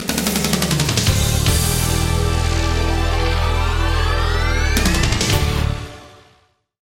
Звуки джинглов
• Качество: высокое
Звуковое оформление радио-новостей